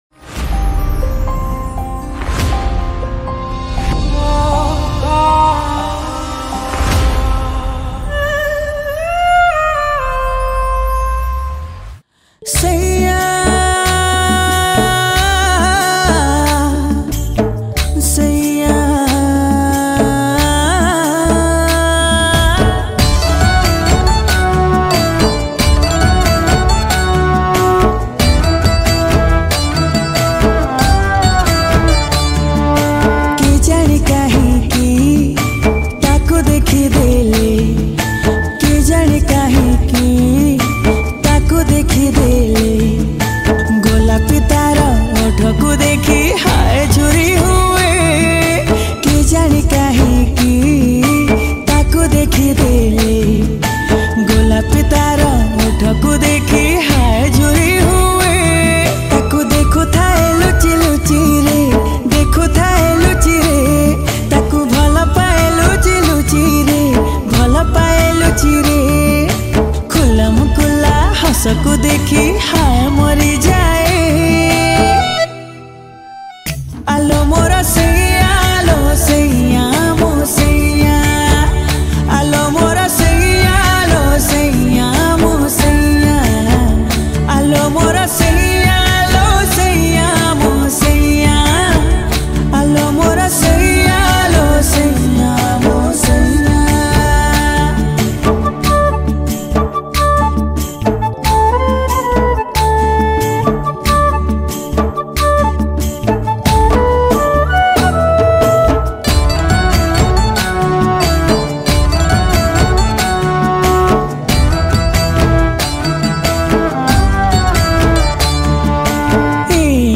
Keaboard